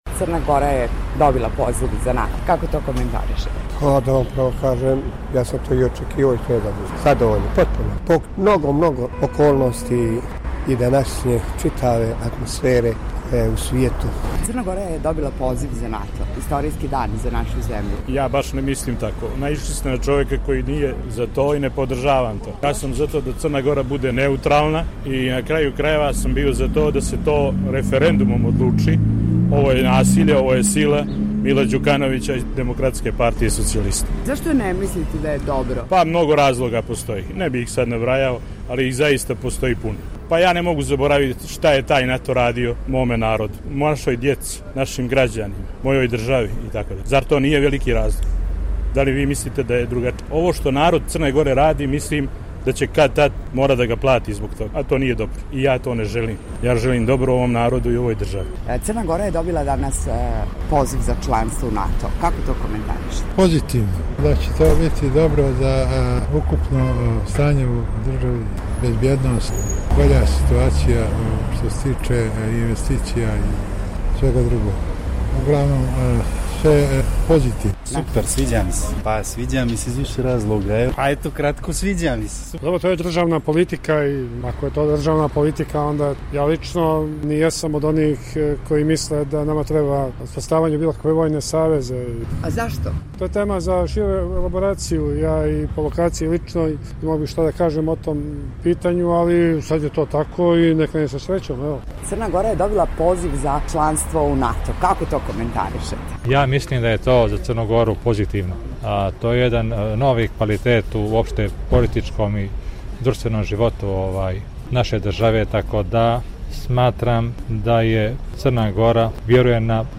U anketi poslušajte mišljenja građana crnogorske prijestolnice o pozivnici za prijem u članstvo Sjevernoatlantskog saveza.